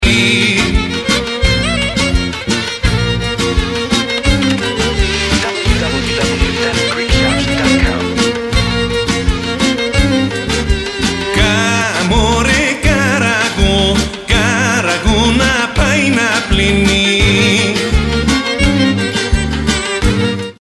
traditional Greek folk music
SIRTA ( KAGGELIA )